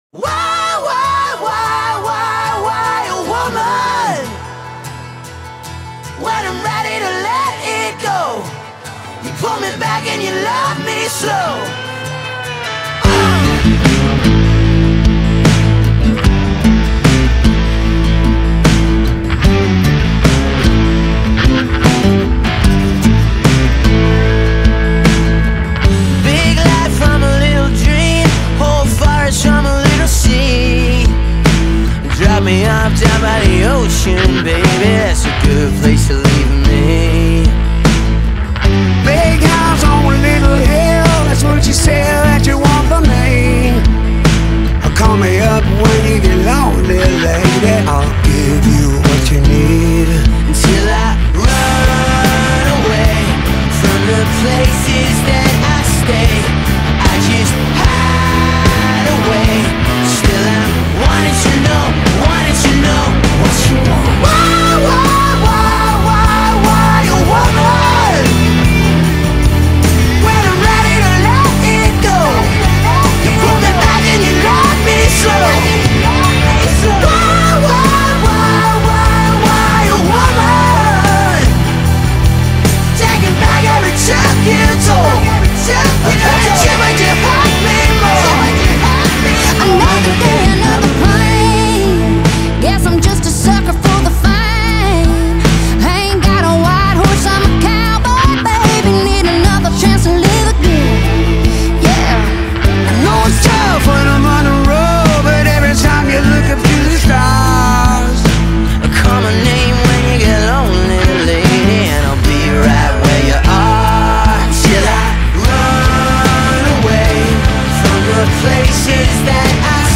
It feels intentional loud and alive.